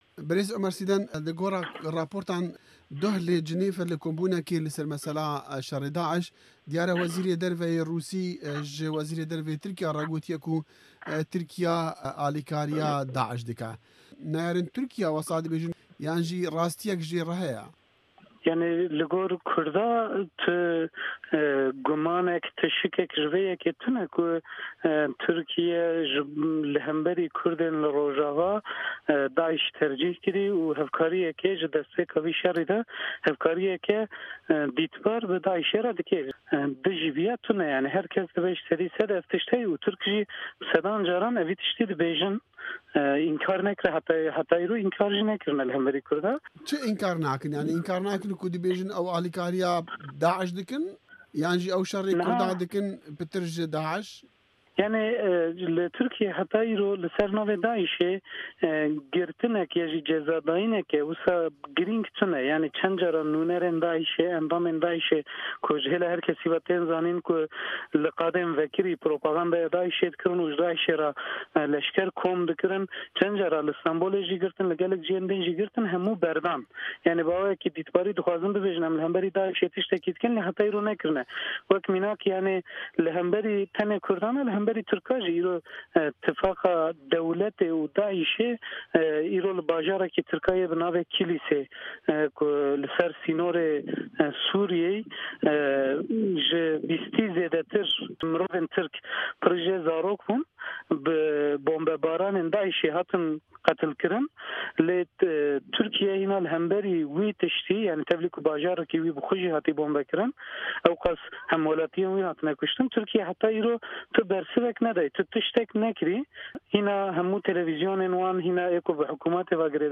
hevpeyvîna